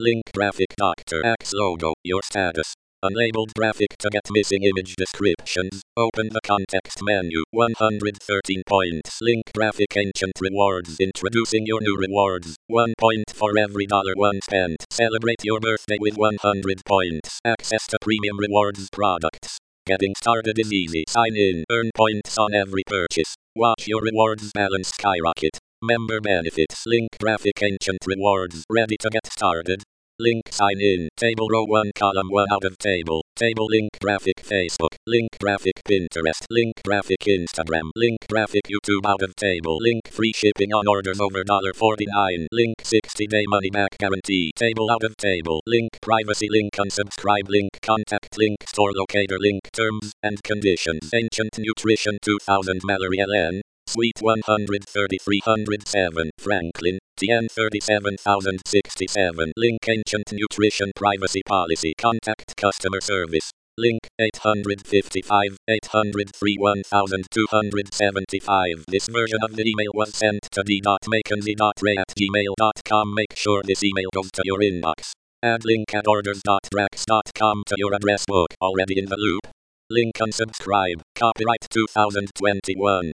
Text to voice recording and transcript for hearing impaired.